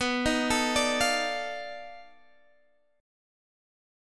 B7#9b5 chord